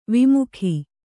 ♪ vimukhi